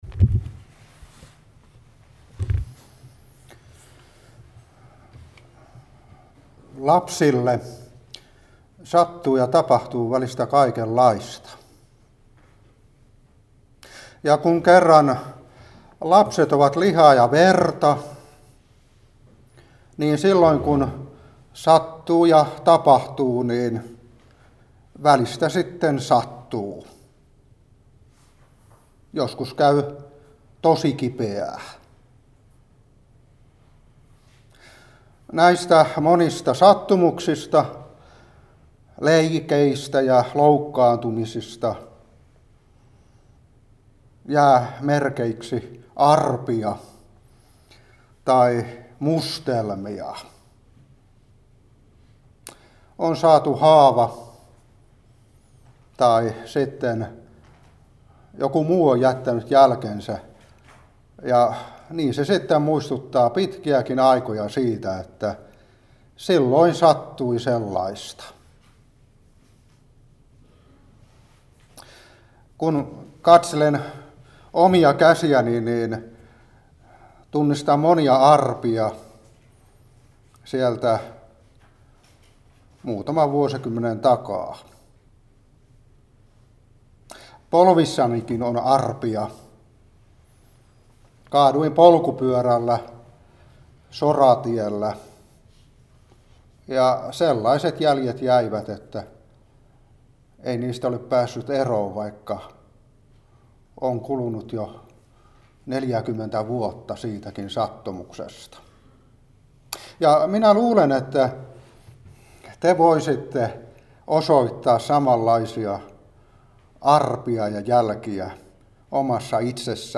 Seurapuhe 2021-12.